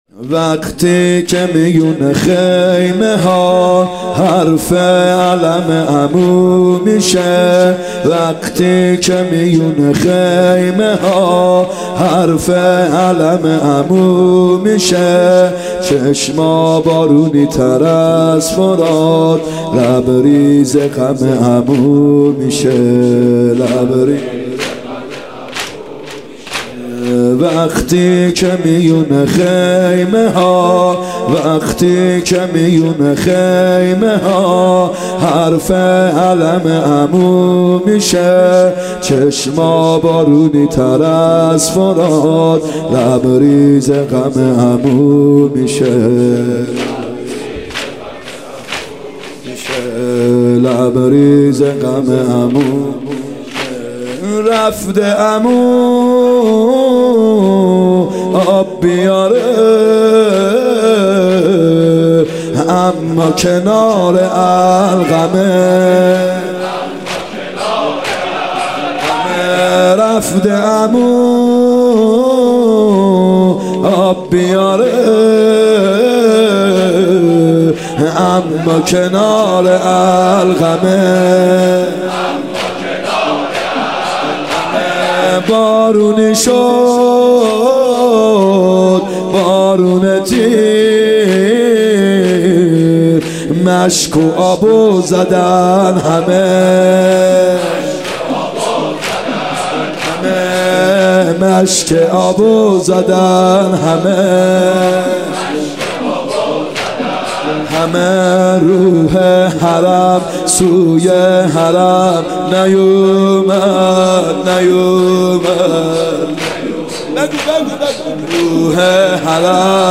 محرم 94 شب نهم زمینه ( وقتی که میون خیمه ها حرف علم عمو میشه
محرم 94(هیات یا مهدی عج)